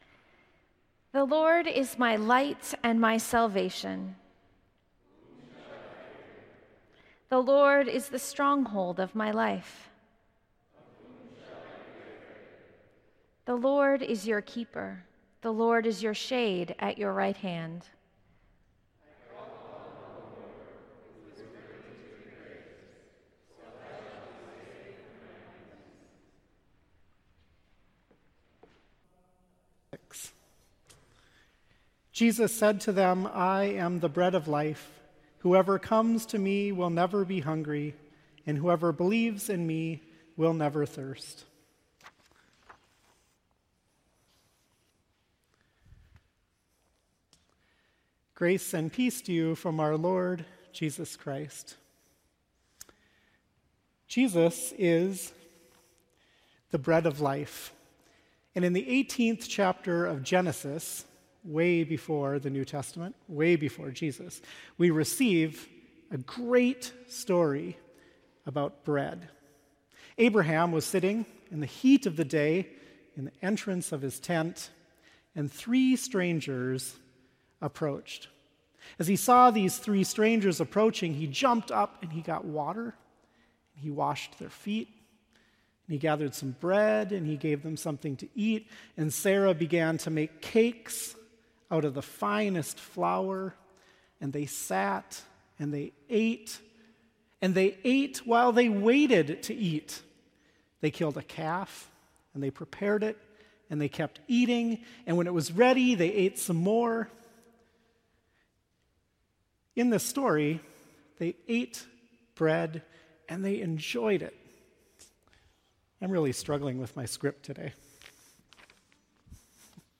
St. Olaf College — Chapel Service for Wed, Mar 09, 2016